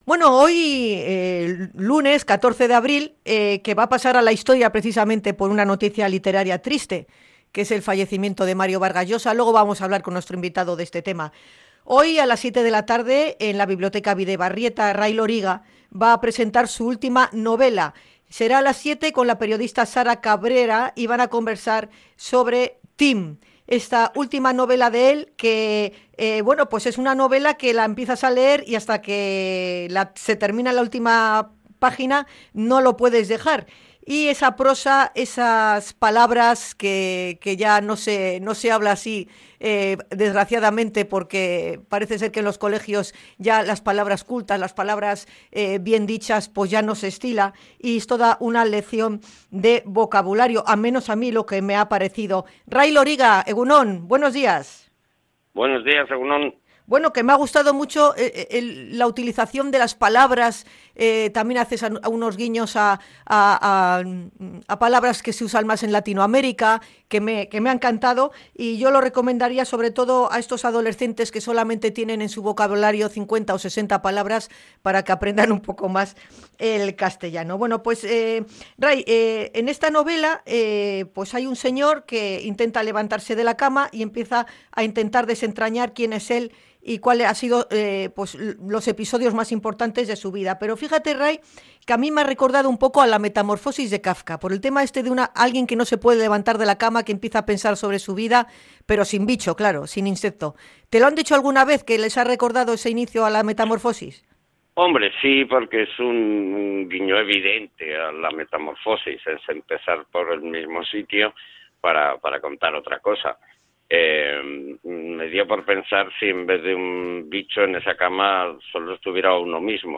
Hablamos con Ray Loriga, que presenta en Bilbao su última novela